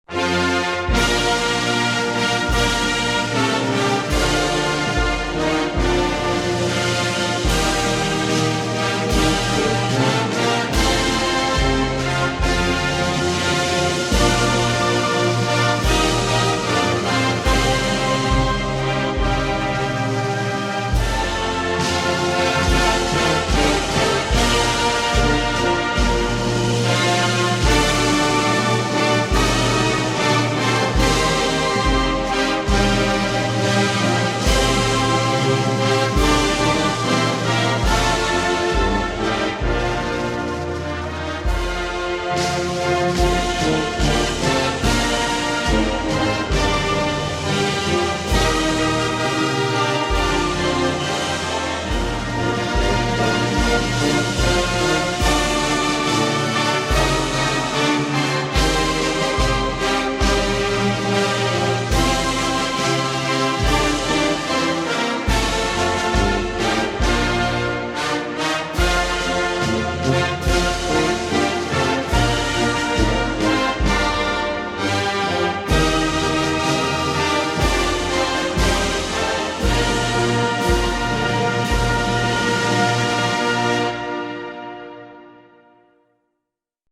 National_Anthem_of_North_Korea_Instrumental.mp3